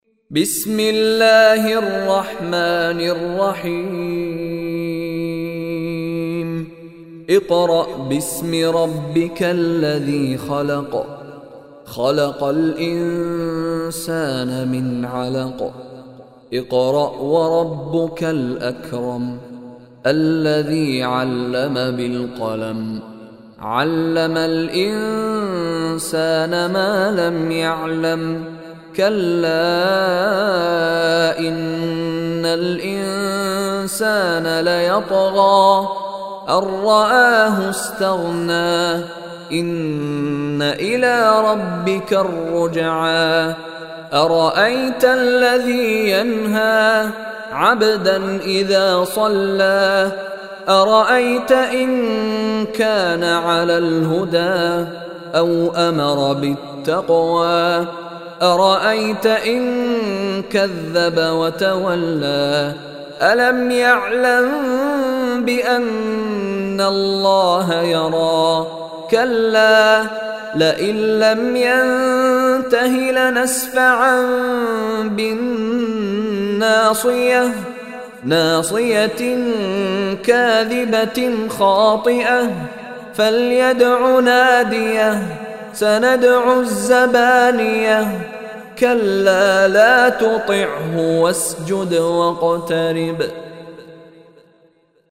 Surah Alaq Recitation by Mishary Rashid Alafasy
Surah Alaq is 96 chapter of Holy Quran. Listen online and download beautiful recitation/ tilawat of Surah Alaq in the voice of Sheikh Mishary Rashid Alafasy.